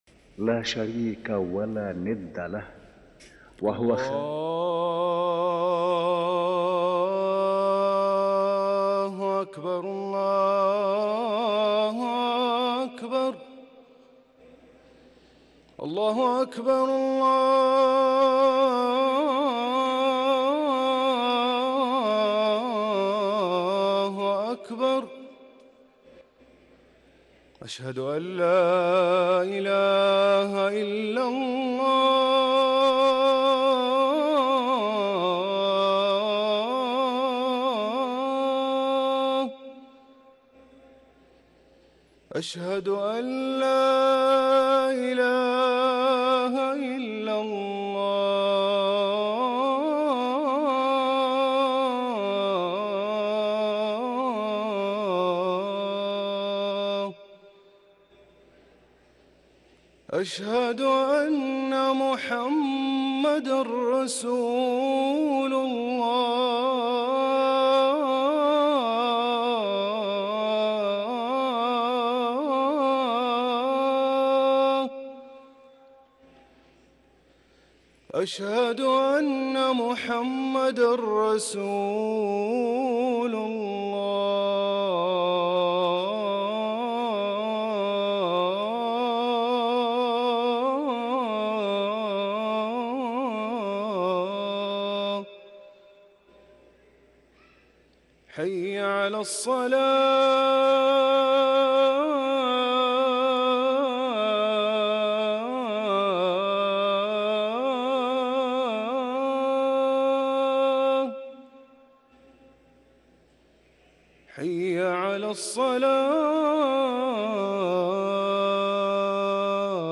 أذان الظهر